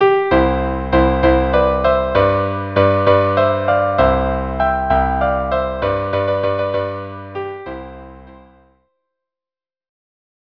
Instrumentation: Violin 1; Violin 2 or Viola; Cello